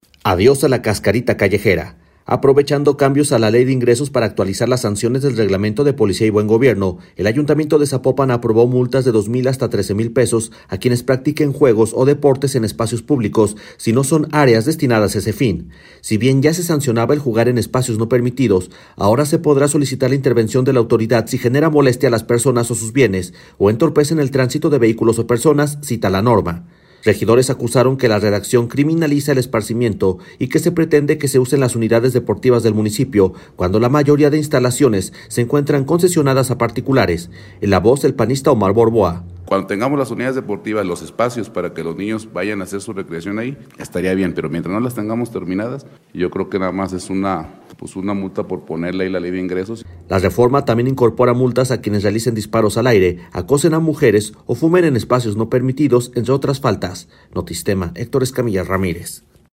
En la voz el panista Omar Borboa.